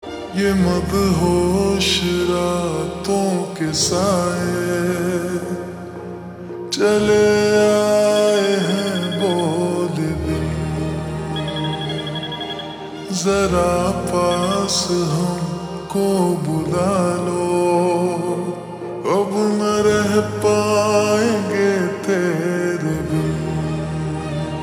Hindi Songs
a soul-stirring melody